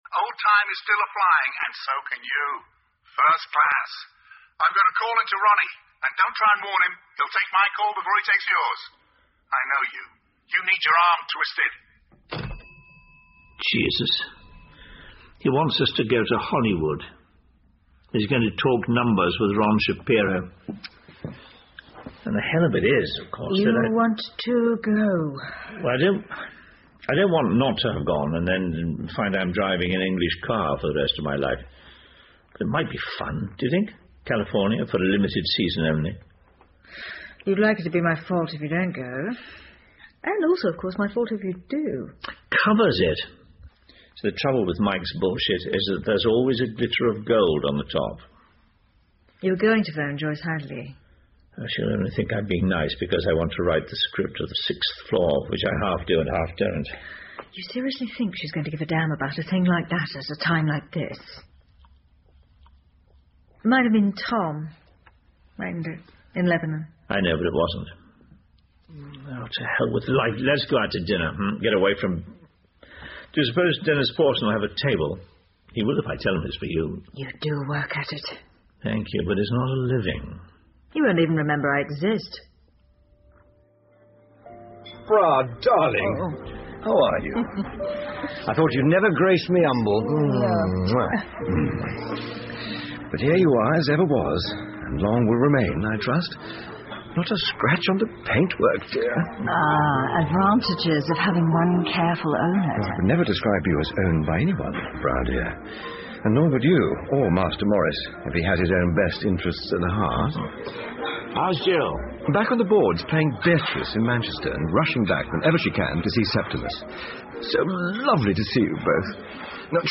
英文广播剧在线听 Fame and Fortune - 37 听力文件下载—在线英语听力室